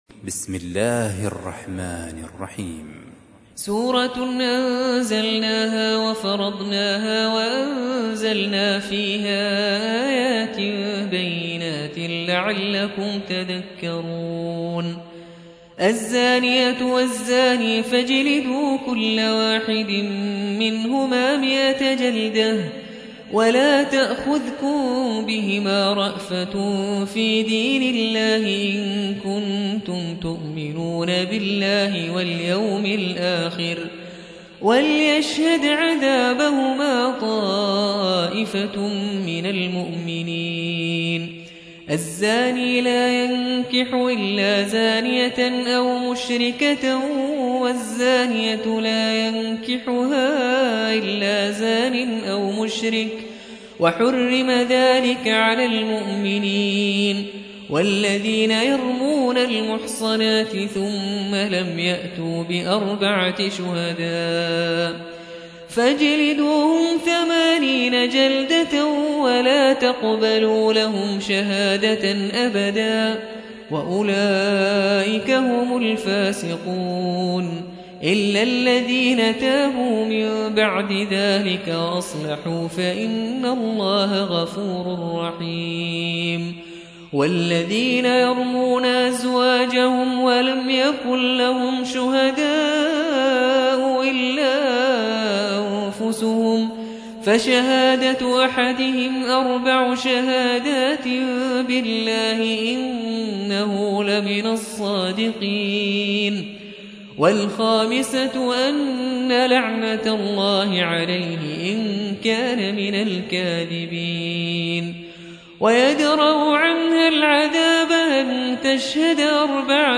تحميل : 24. سورة النور / القارئ نبيل الرفاعي / القرآن الكريم / موقع يا حسين